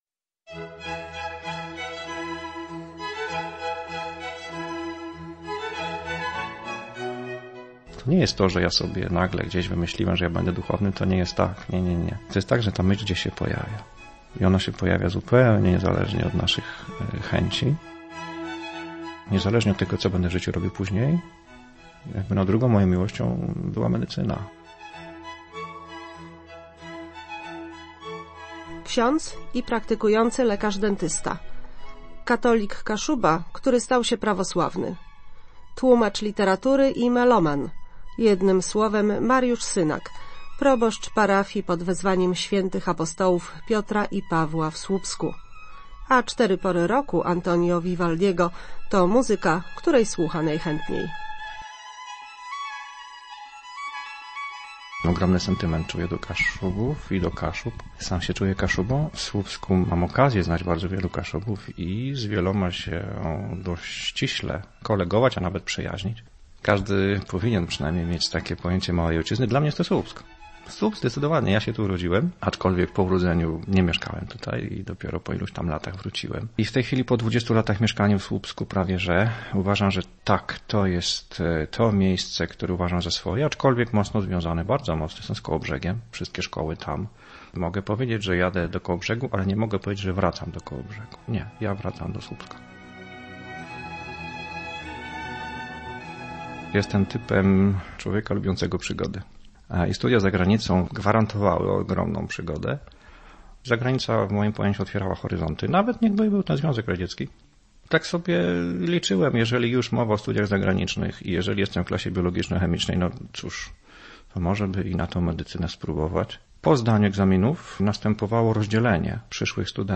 Radio Orthodoxia "Pogranicze" - wywiad z ks.